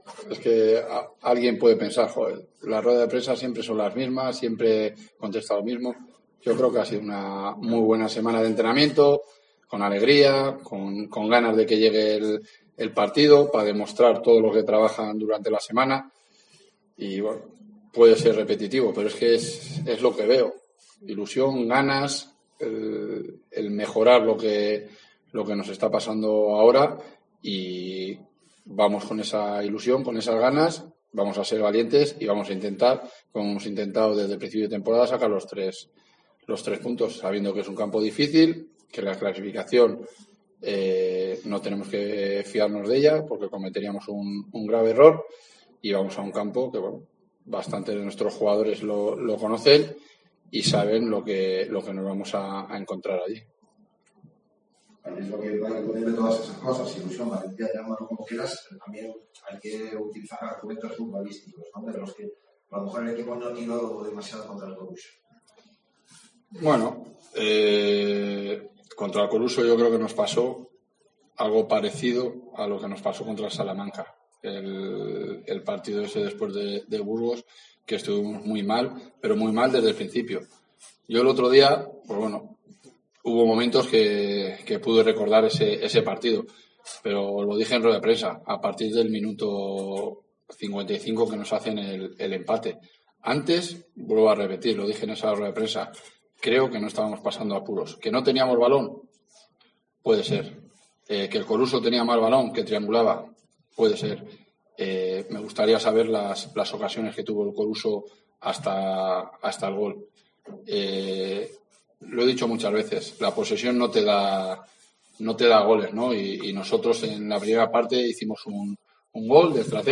El entrenador de la Deportiva Ponferradina, Jon Pérez Bolo, ha analizado en la sala de prensa de El Toralín, el partido de este domingo a las 17:00 horas en tierras gallegas ante Rápido de Bouzas.